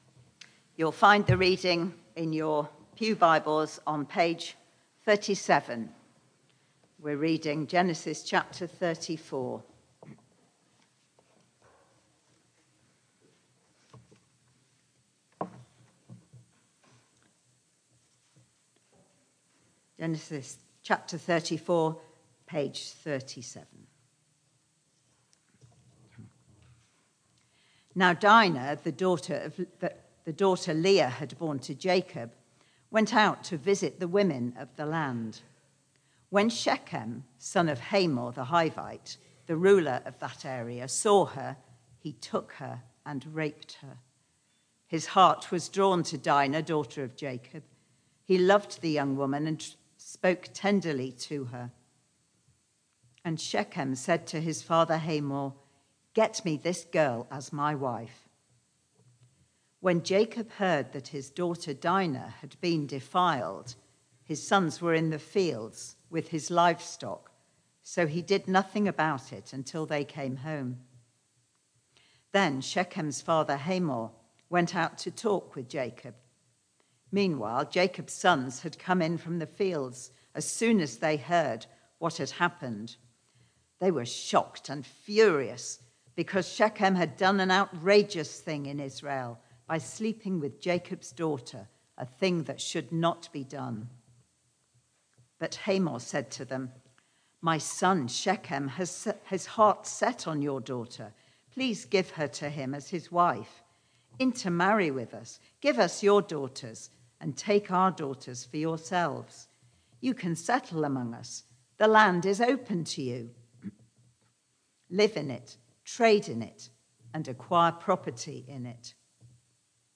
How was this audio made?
Media for Barkham Morning Service